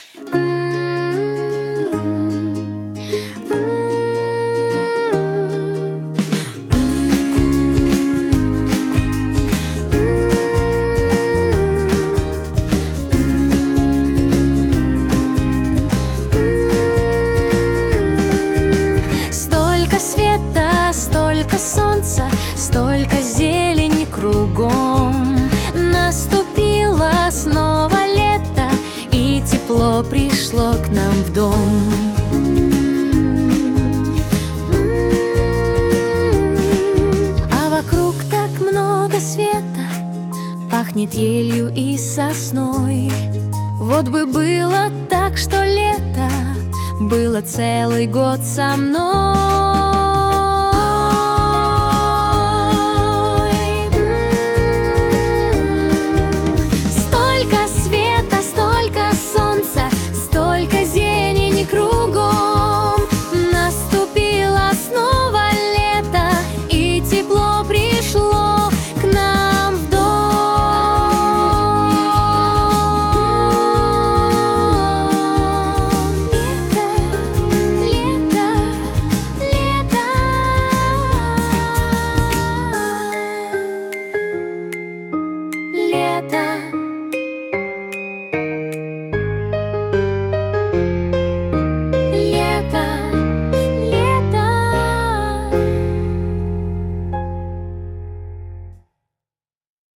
минус Категория: Детские песни В закладки 😡 Замечание!